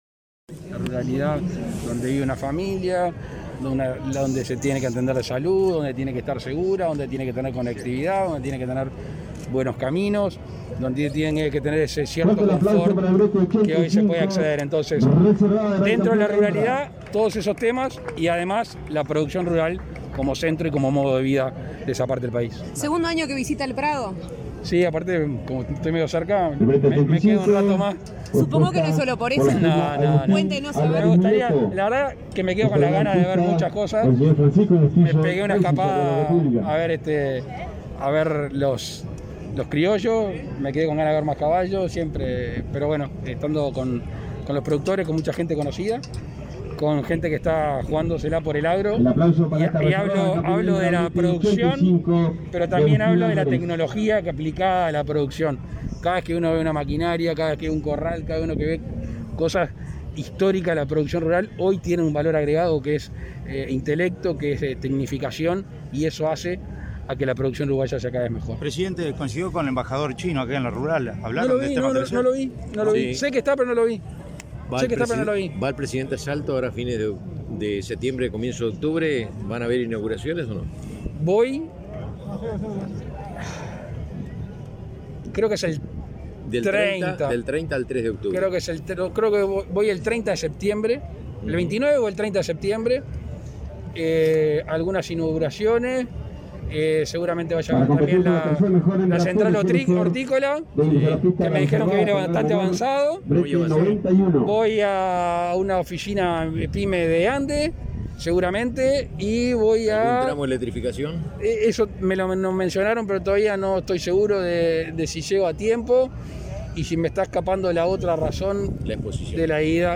Declaraciones a la prensa del presidente de la República, Luis Lacalle Pou
Declaraciones a la prensa del presidente de la República, Luis Lacalle Pou 15/09/2021 Compartir Facebook X Copiar enlace WhatsApp LinkedIn El mandatario visitó la Expo Prado 2021, este 15 de setiembre, jornada en la que participó en la premiación de la raza polled hereford. Tras el evento, efectuó declaraciones a la prensa.